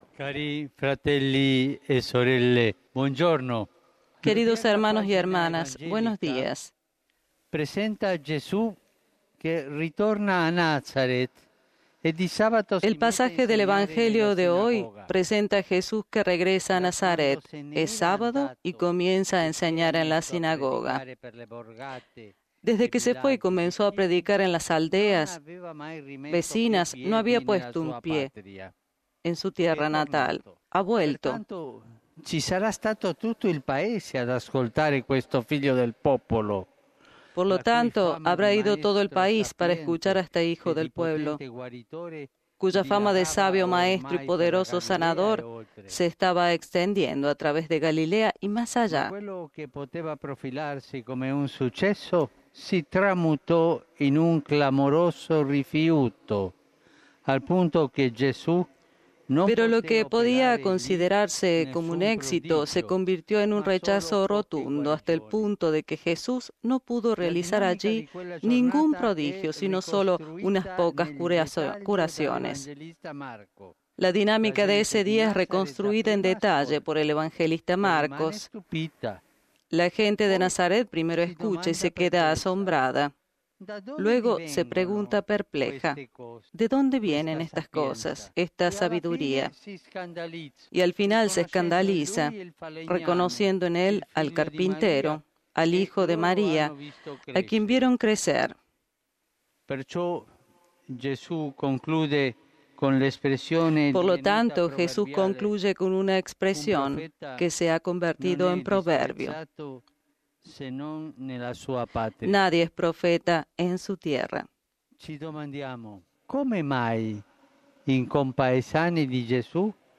El 8 de julio, XIV Domingo del tiempo ordinario, el Papa Francisco rezó la oración mariana del Ángelus, como es habitual, desde la ventana del Palacio Apostólico acompañado por miles de fieles y peregrinos reunidos en la Plaza de San Pedro.
El Papa a la hora del Ángelus